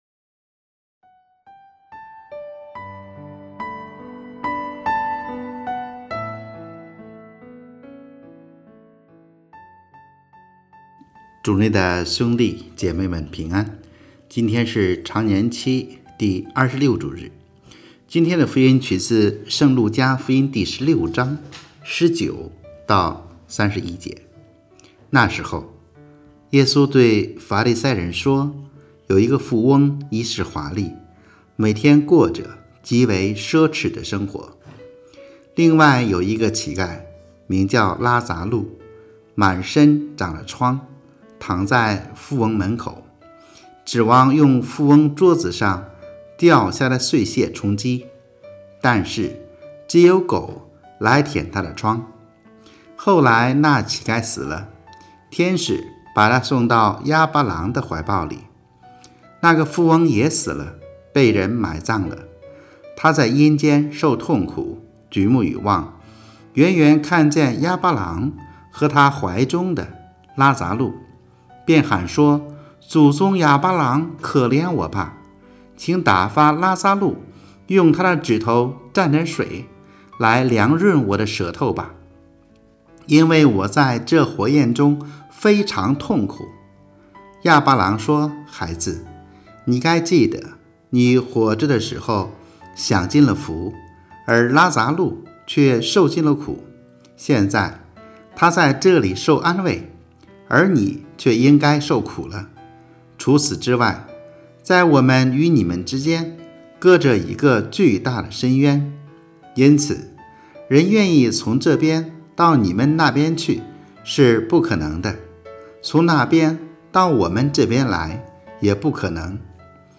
【主日证道】|今生选择永生（常26主日）